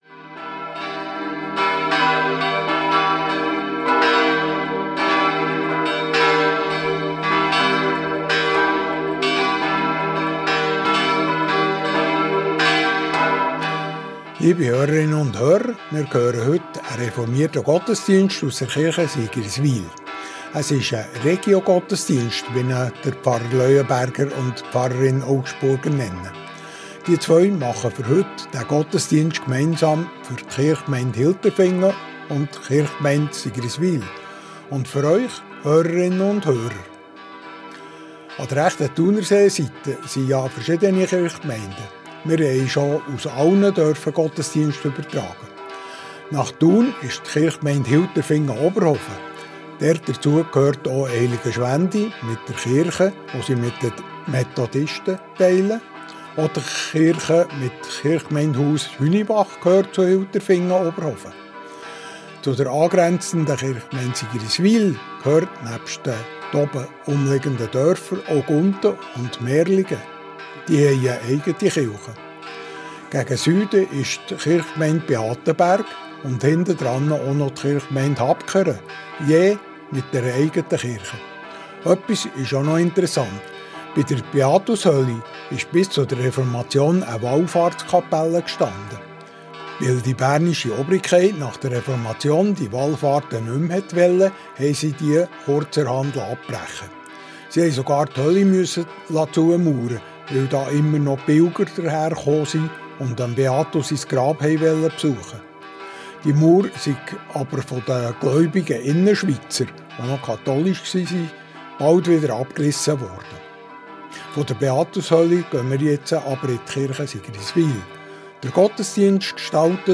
Reformierte Kirche Sigriswil ~ Gottesdienst auf Radio BeO Podcast
BeO Gottesdienst